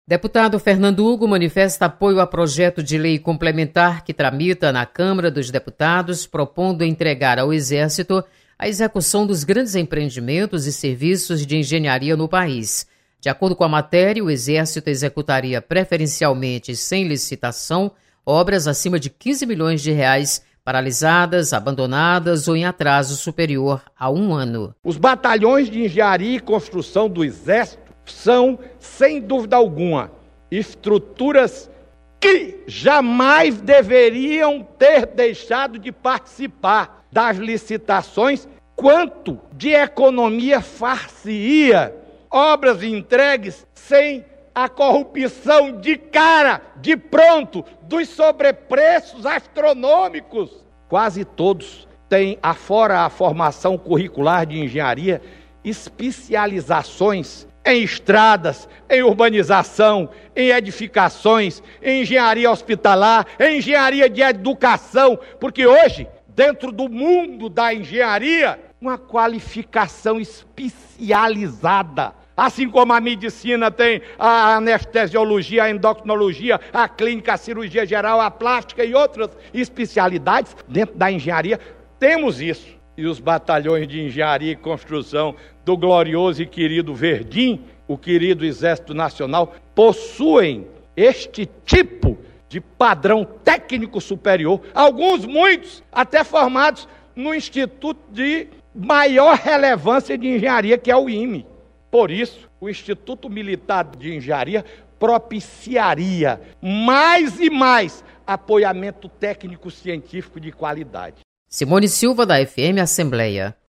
Deputado Fernando Hugo defende participação do Exército em licitações. Repórter